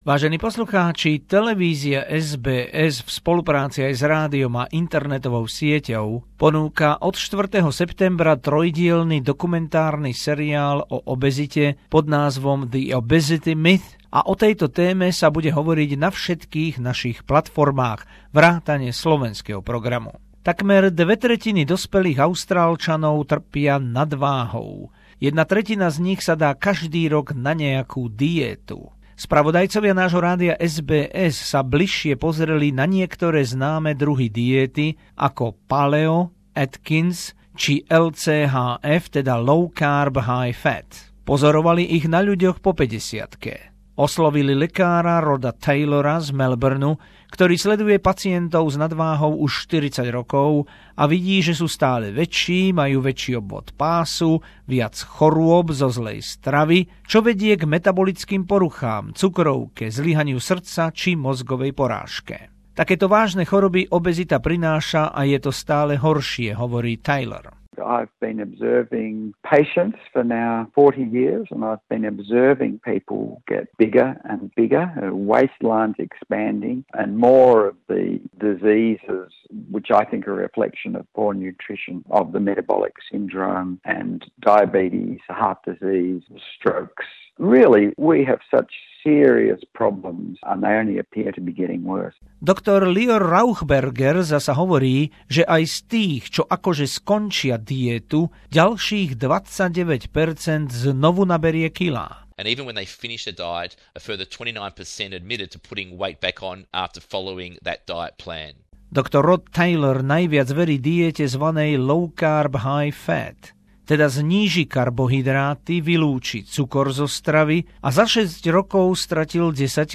Feature from the VIVA Obesity Myth series, Whats LCHF/Paleo/Atkins diet?